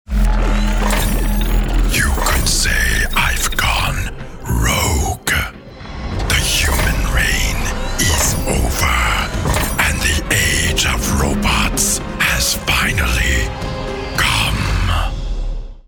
Conversational, Warm, Easy-Listening, Smooth, Friendly, Relatable, Interesting, Informative, Clean, Soft-Sell, Confident, Knowledgeable The kind of voice you could listen to for hours....